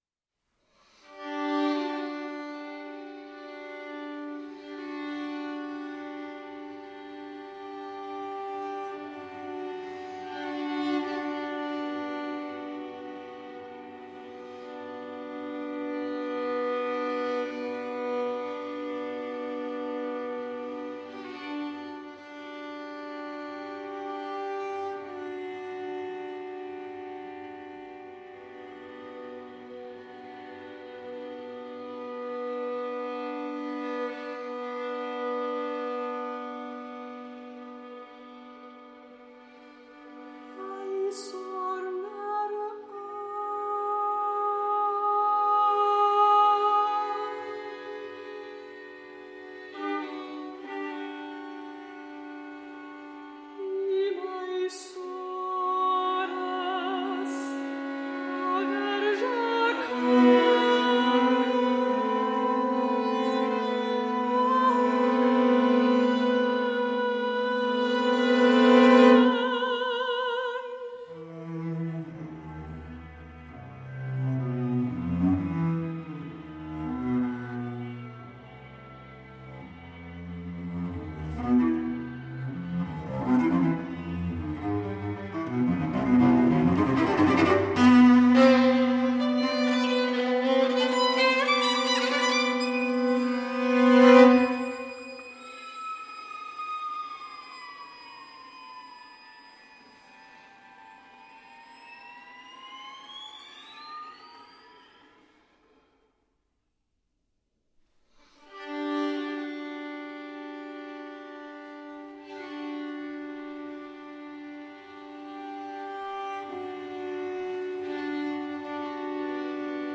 mezzo-soprano
viola